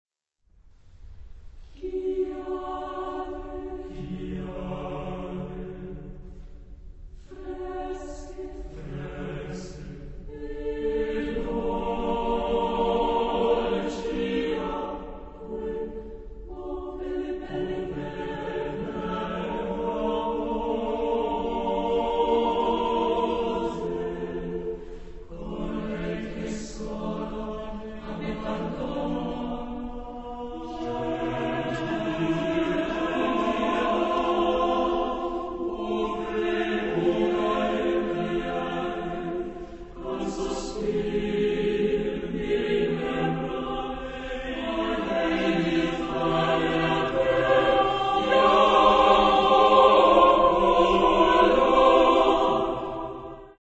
Época : Siglo 20
Tipo de formación coral: SATB  (4 voces Coro mixto )